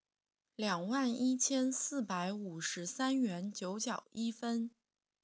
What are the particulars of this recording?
Mandarin_Voiceprint_Recognition_Speech_Data_by_Mobile_Phone